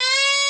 D3FLUTE83#08.wav